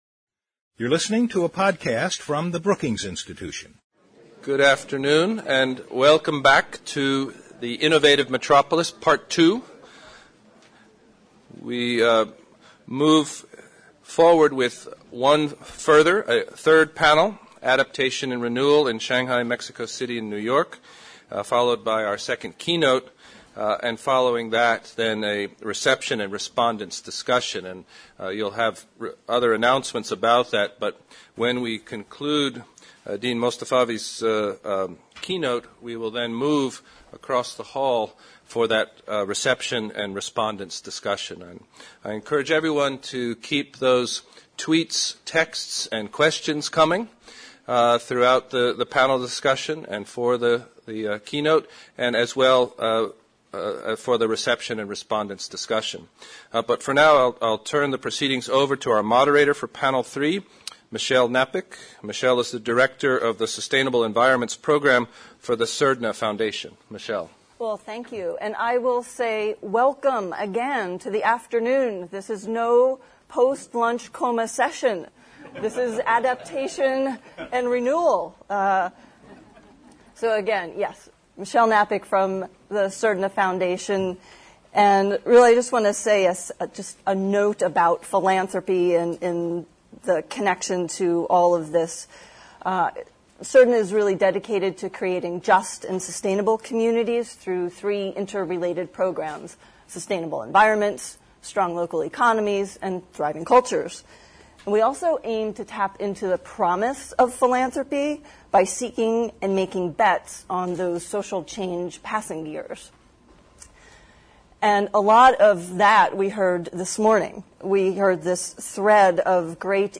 On February 21, the Metropolitan Policy Program at Brookings and the Sam Fox School’s Master of Urban Design Program hosted an all-day forum which explored the intersection between sustainable urban design and economic growth while discussing the implications for design and practice. The event also highlighted policies that have enabled individual cities to become successful models of sustainability.